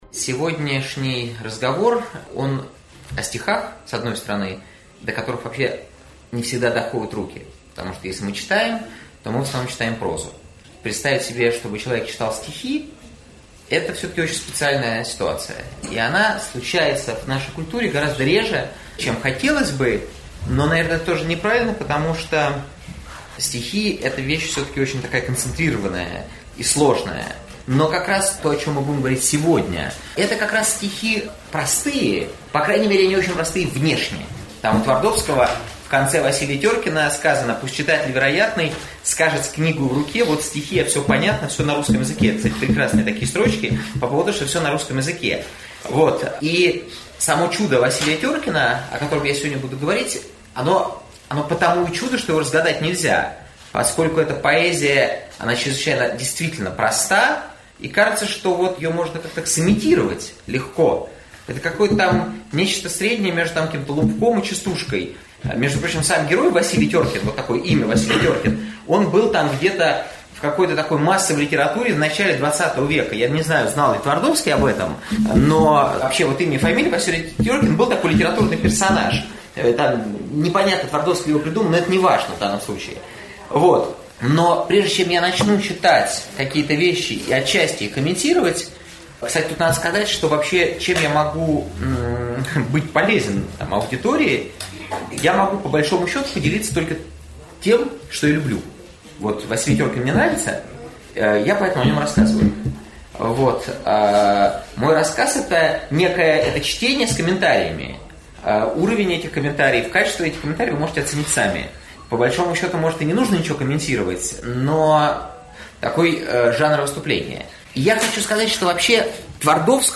Аудиокнига Твардовский. Василий Теркин. Ради жизни на Земле | Библиотека аудиокниг